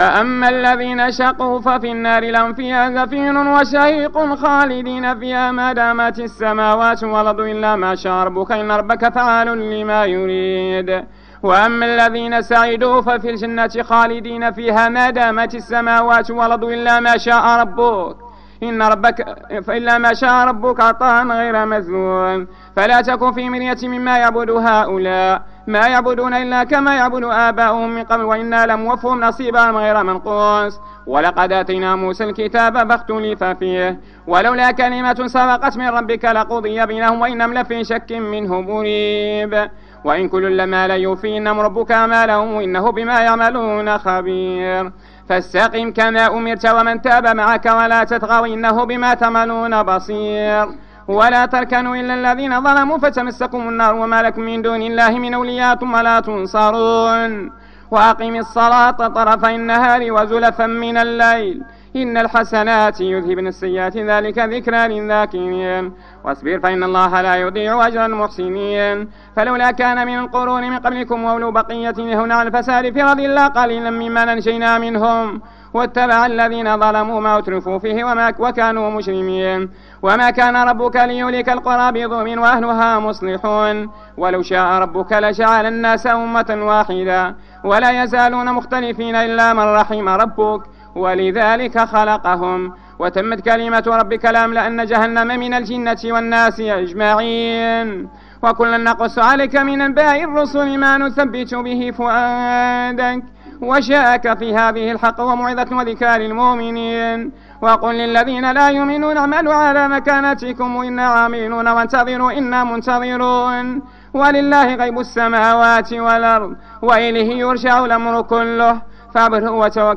صلاة التراويح رقم 02 بمسجد ابي بكر الصديق فقارة الزوى